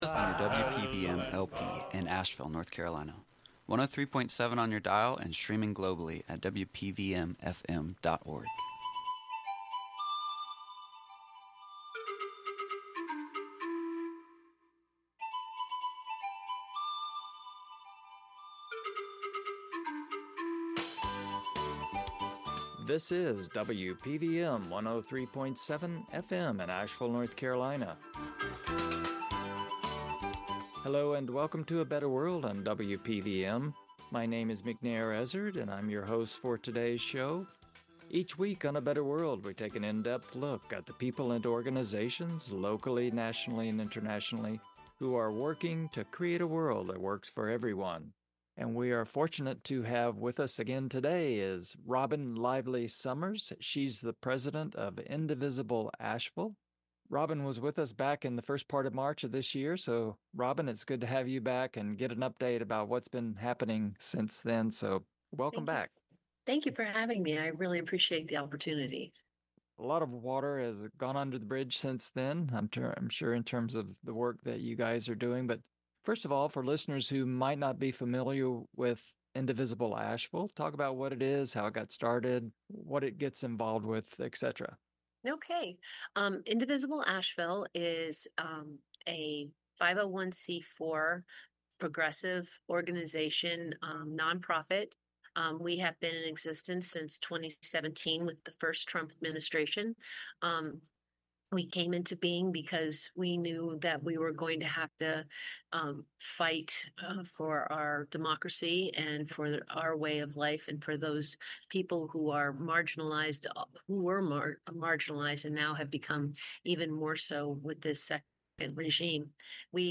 A Better World radio show